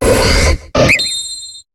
Cri de Gallame dans Pokémon HOME.